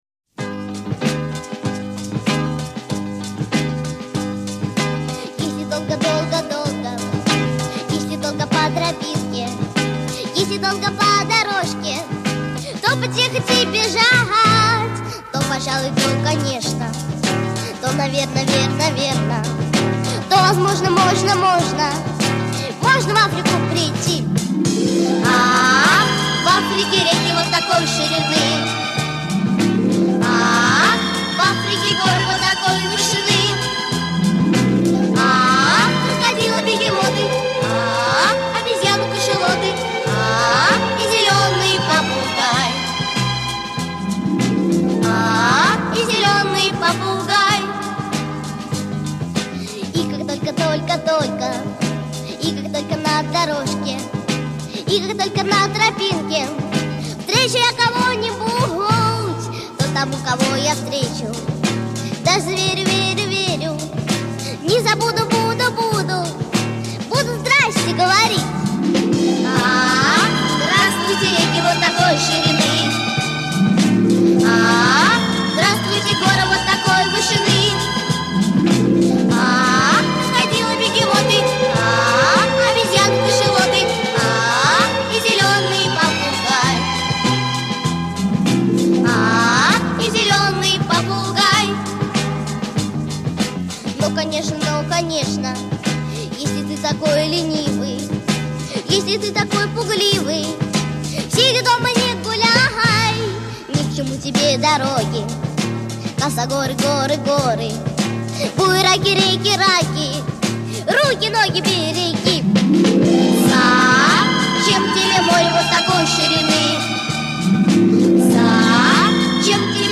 добрая, заманчивая и легко запоминающаяся